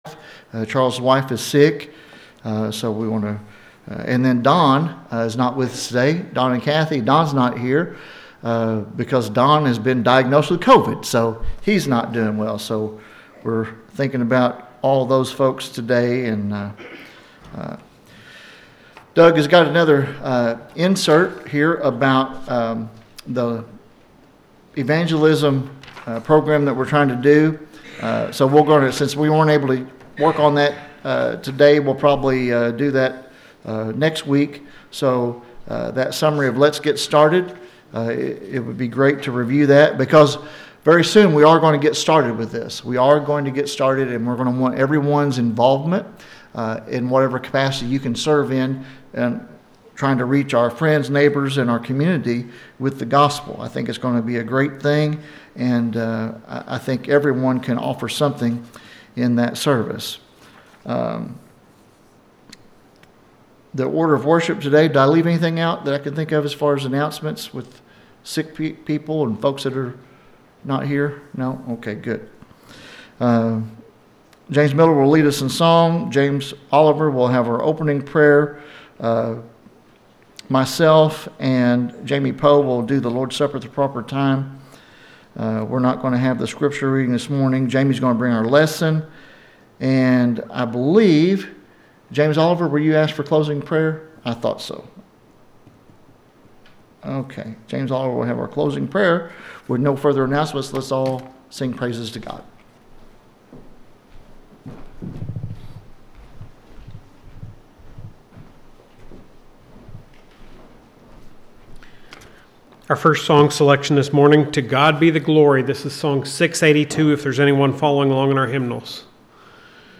The sermon is from our live stream on 12/28/2025